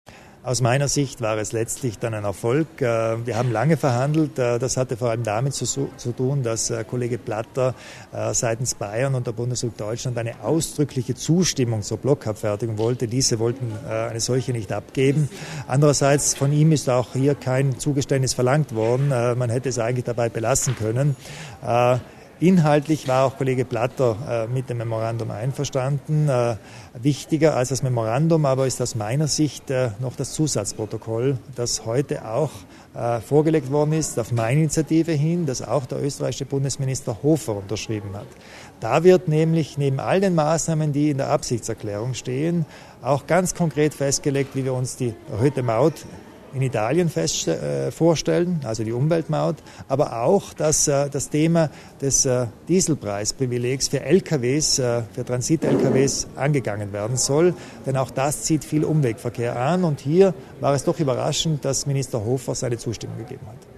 Landeshauptmann Kompatscher zieht Bilanz über das zweite Brenner Meeting